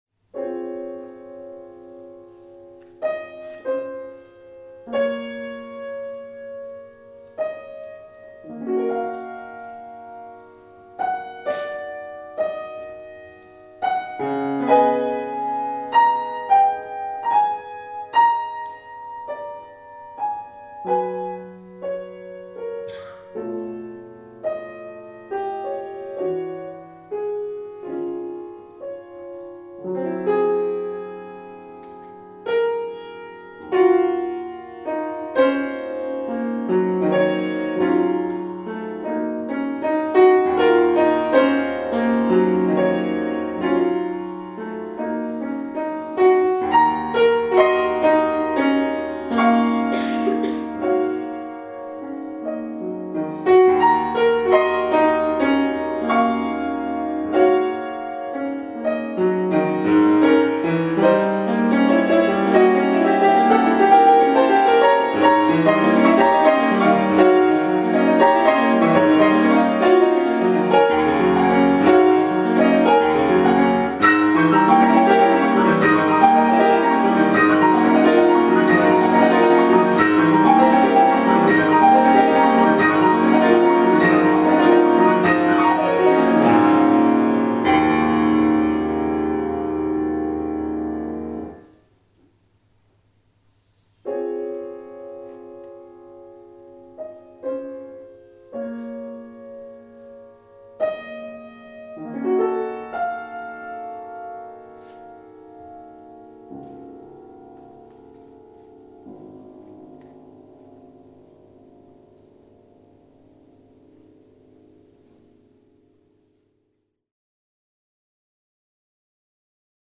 Live Recording
The University of Zululand